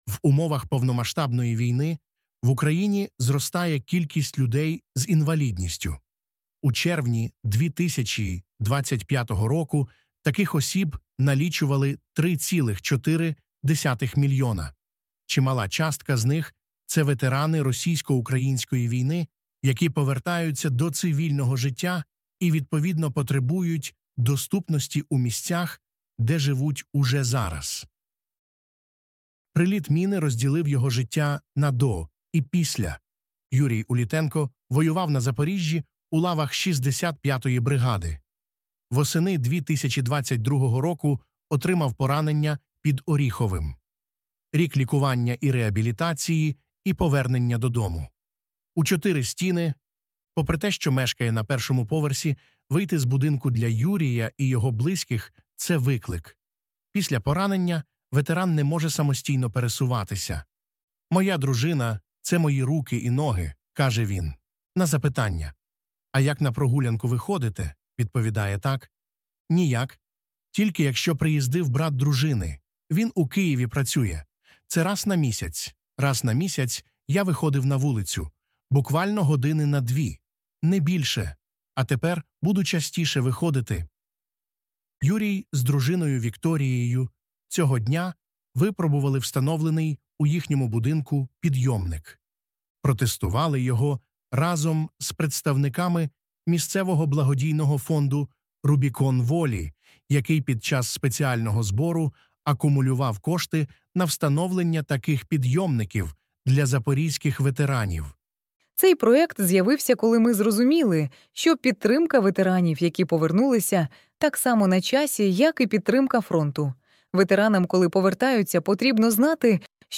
Це аудіо озвучено з допомогою штучного інтелекту